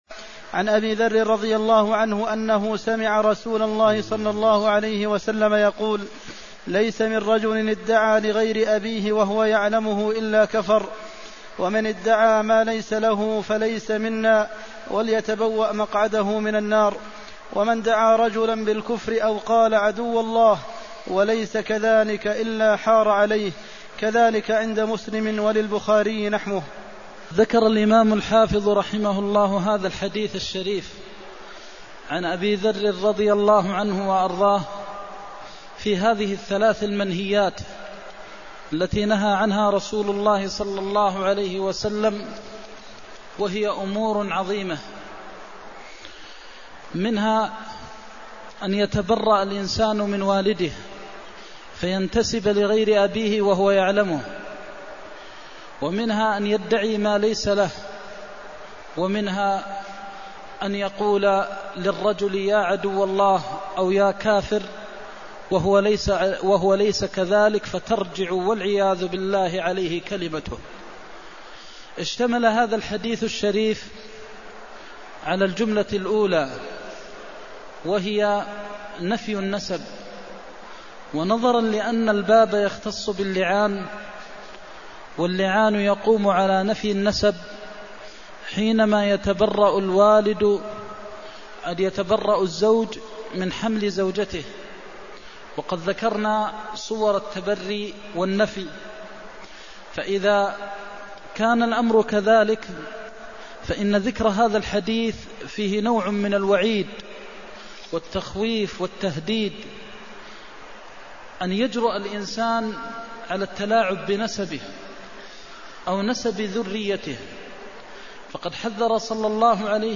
المكان: المسجد النبوي الشيخ: فضيلة الشيخ د. محمد بن محمد المختار فضيلة الشيخ د. محمد بن محمد المختار ليس من رجل ادعى لغير أبيه وهو يعلمه إلا كفر (313)